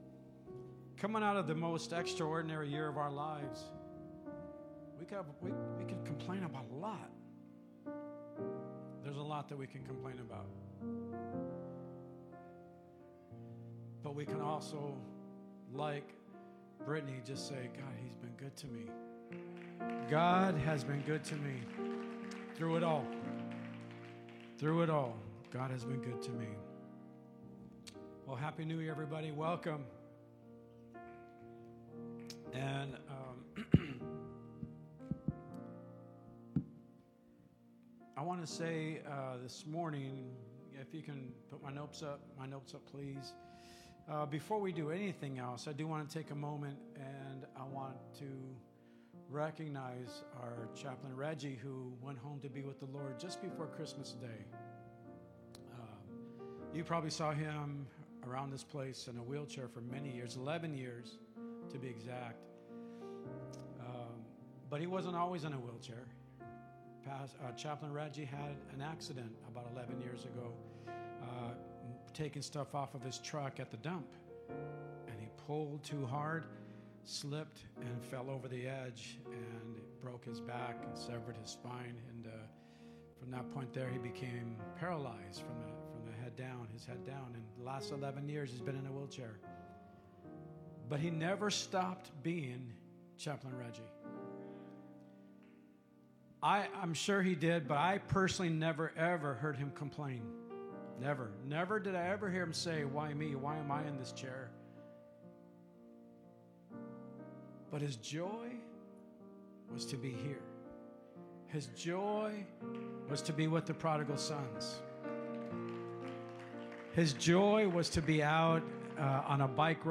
Sermons | CWC LIFE Manteca CA